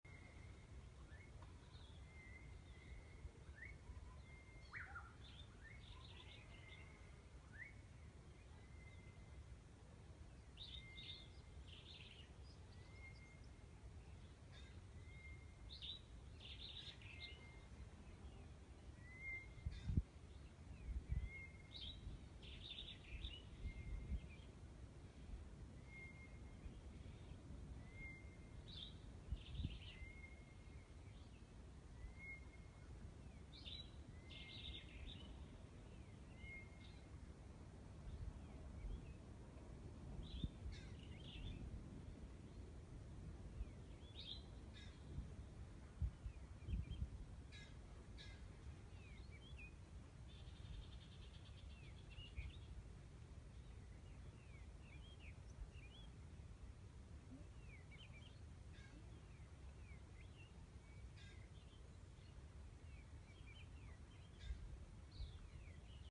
Take a listen to the early morning sounds of the bush in the Eastern Cape, South Africa.